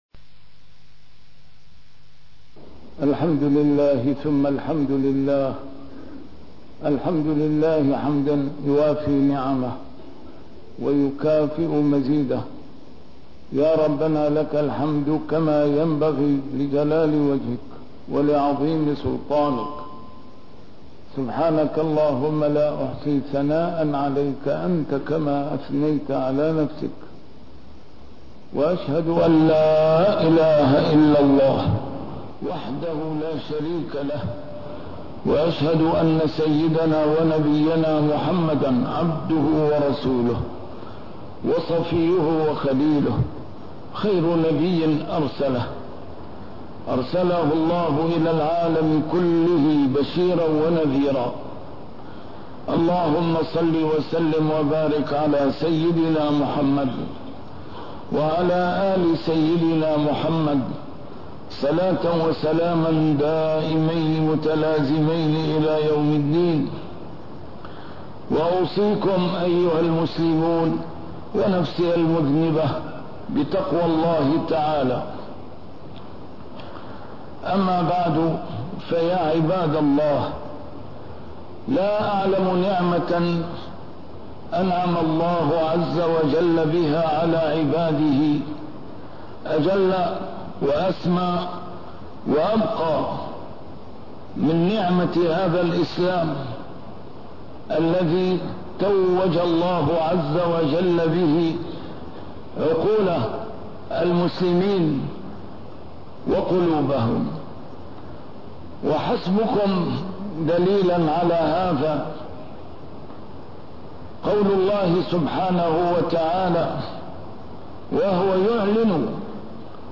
A MARTYR SCHOLAR: IMAM MUHAMMAD SAEED RAMADAN AL-BOUTI - الخطب - ثلاثة.... يزال الهرج بزوالها